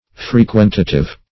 Frequentative \Fre*quent"a*tive\, a. [L. frequentativus: cf. F.